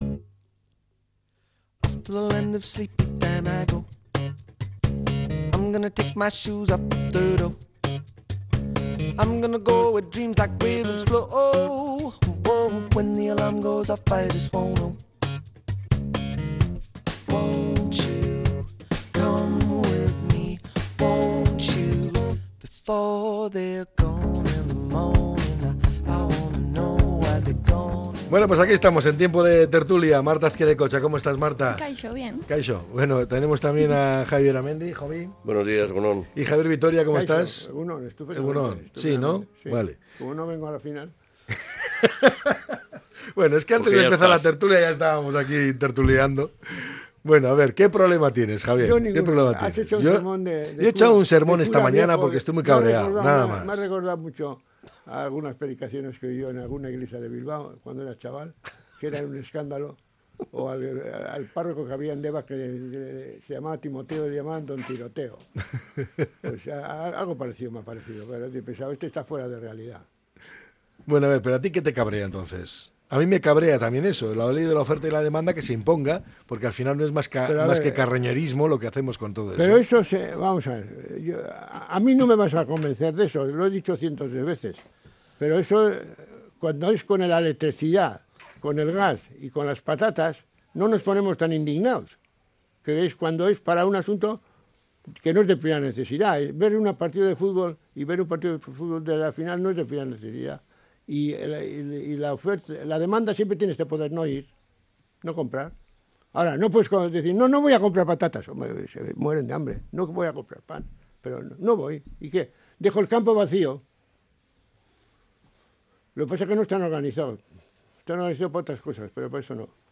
La tertulia 13-05-25.